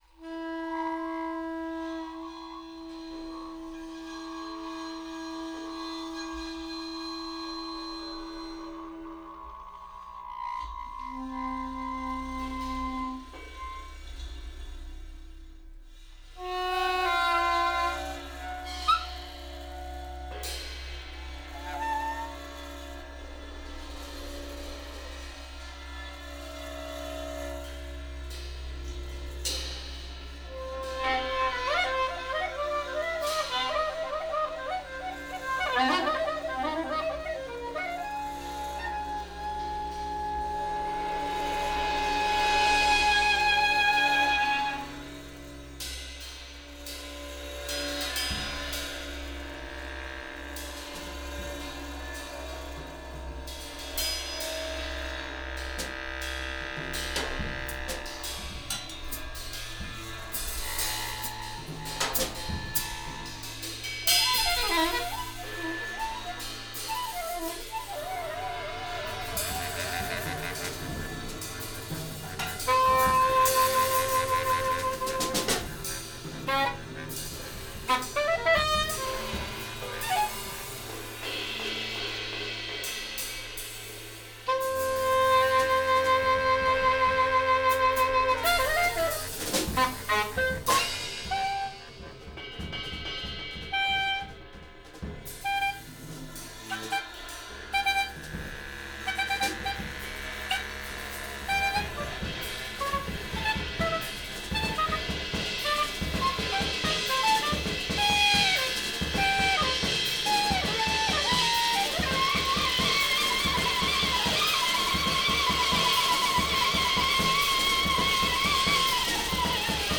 Freejazz/Experimentation
laptop, found objects
and drums
saxophone
bass clarinet